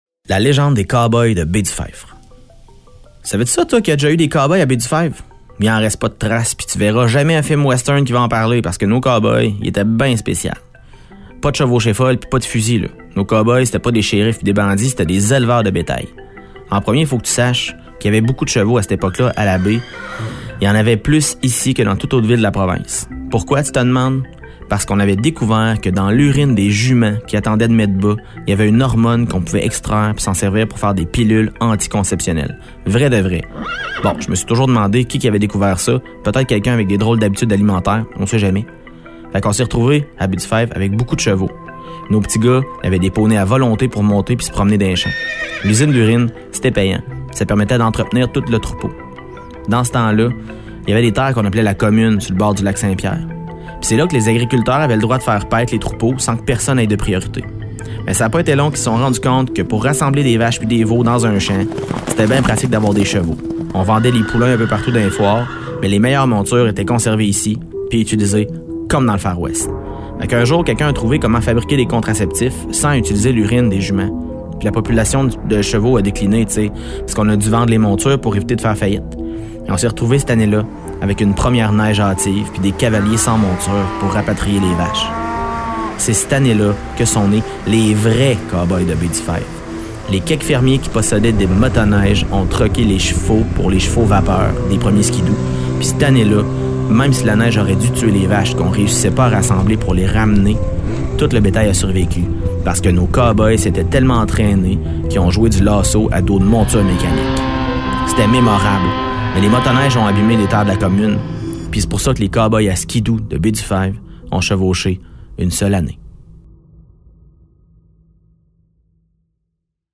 Ce conte fait partie d’un projet initié par la MRC de Nicolet-Yamaska dans le cadre de son Entente de développement culturel avec le ministère de la Culture et des Communications et réalisé de concert avec VIA 90.5 FM.